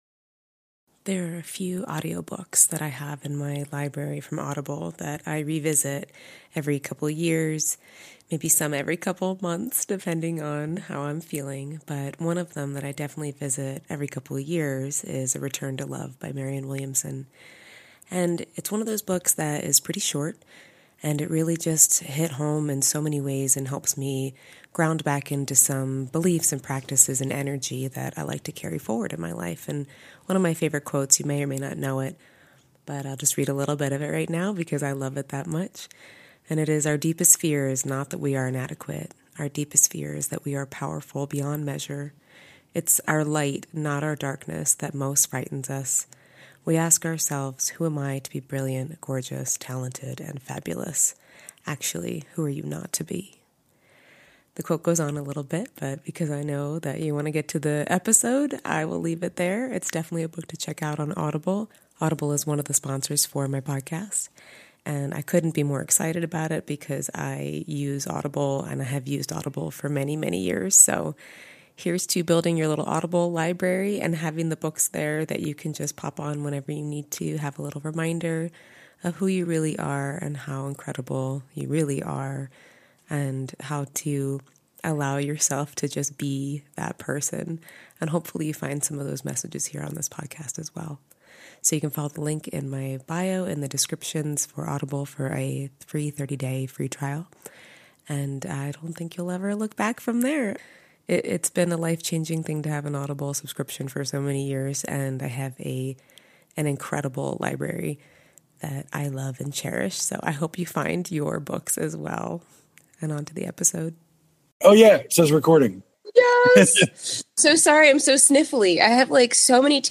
The audio has a few spotty moments (I’m still getting the hang of remote interviews!), but I’m embracing my own advice: dive in and do the thing instead of waiting for perfection.
This week, I’m bringing you a special interview with one of my best friends, longtime confidants, and all-around incredible humans—Jess Margera.
Jess-s-Interview-Audio-Only-converted.mp3